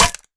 Minecraft Version Minecraft Version snapshot Latest Release | Latest Snapshot snapshot / assets / minecraft / sounds / mob / goat / horn_break2.ogg Compare With Compare With Latest Release | Latest Snapshot
horn_break2.ogg